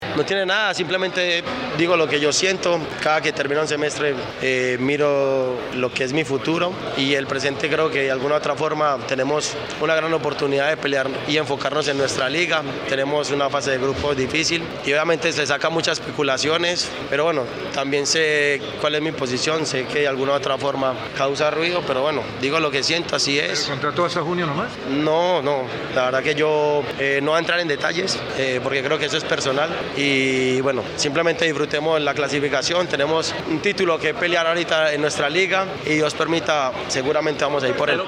No todo fue euforia y felicidad para los seguidores americanos, pues, posterior al partido, Juan Fernando Quintero estuvo en conferencia de prensa y dejó en el aire su continuidad con el club, además, confesó que en cada mercado de transferencias, siempre se le liga con su antiguo equipo, River Plate.